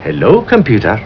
Computer (12 kb, .wav)
computer.wav